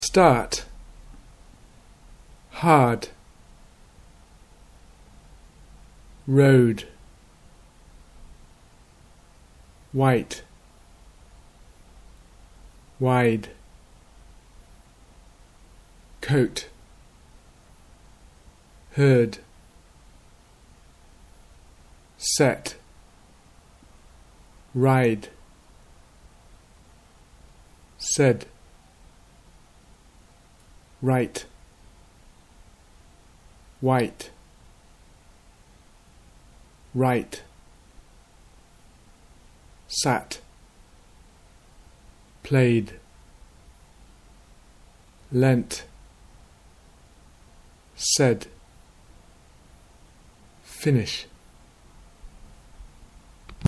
hard v heart - final devoicing
minimal pair discrimination